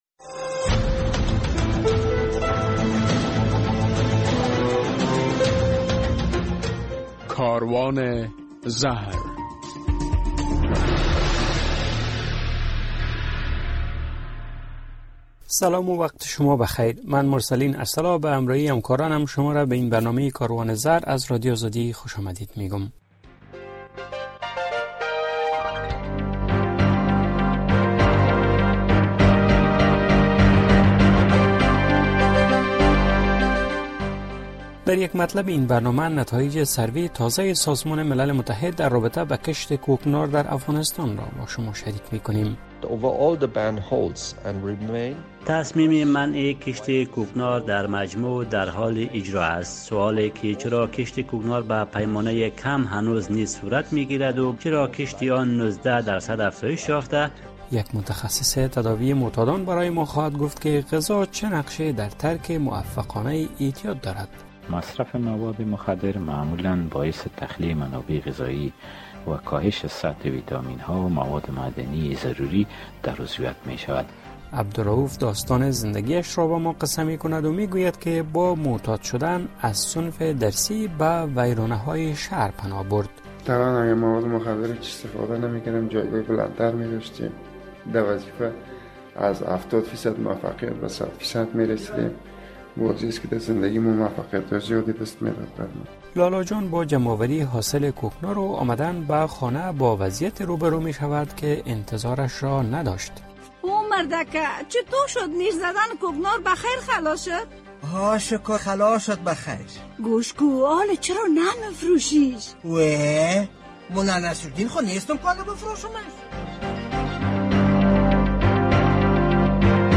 در این برنامه کاروان زهر می‌شنوید که سازمان ملل متحد می‌گوید امسال بر بیش از دوازده هزار هکتار زمین در افغانستان کوکنار کشت شده بود. در مصاحبه با یک داکتر متخصص از وی پرسیدیم که چرا هنگام ترک اعتیاد اشتهای معتاد بهبود می یابد و برای ترک موفقانه مواد مخدر باید به وی چی نوع غذا داده شود؟